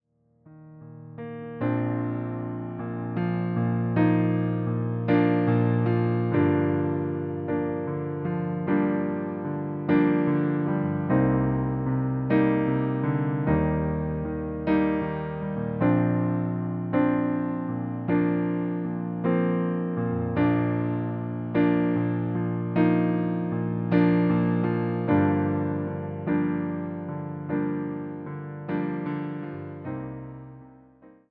Wersja demonstracyjna:
51 BPM
B – dur